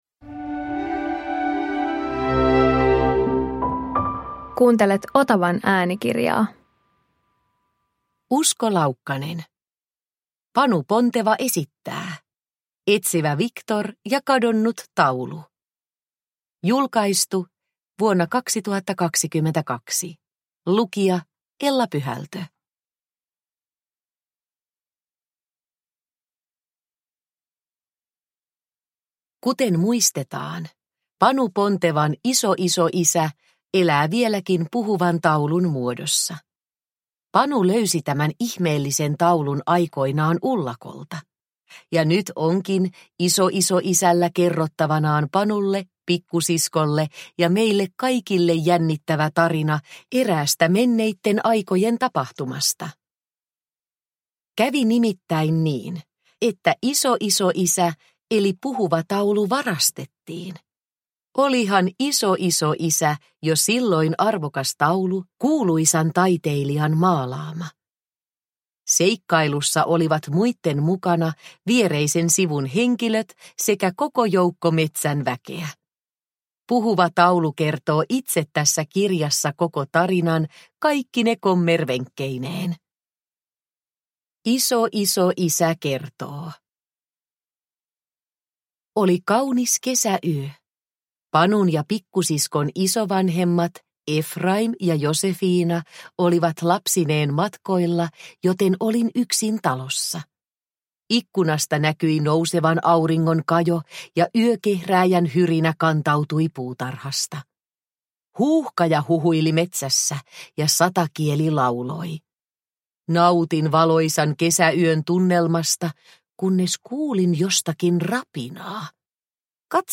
Etsivä Wiktor ja kadonnut taulu – Ljudbok – Laddas ner